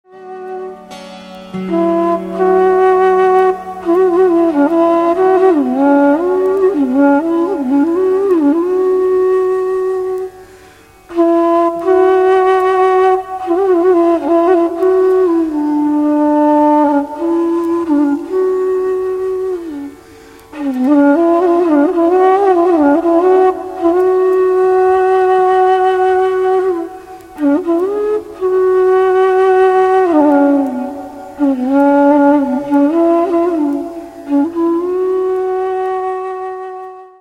Флейта
bansree.mp3